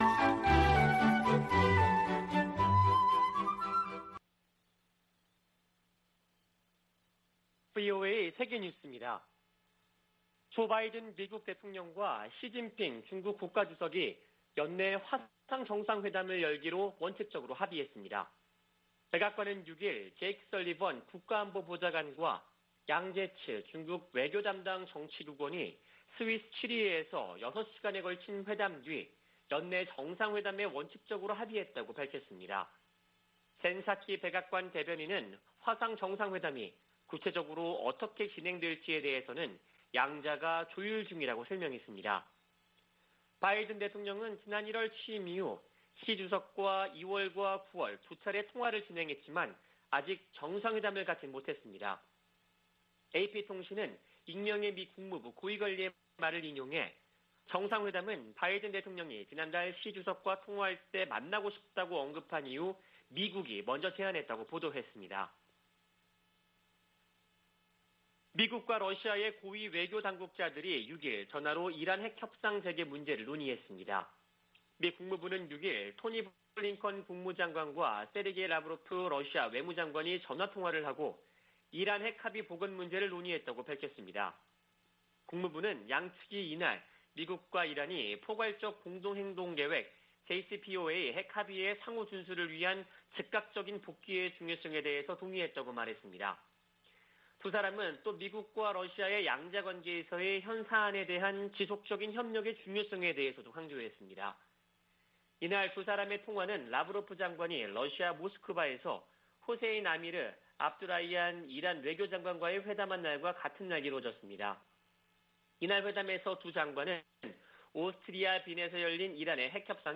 VOA 한국어 아침 뉴스 프로그램 '워싱턴 뉴스 광장' 2021년 10월 8일 방송입니다. 북한이 영변 핵시설 내 우라늄 농축공장 확장 공사를 계속하고 있는 것으로 파악됐습니다.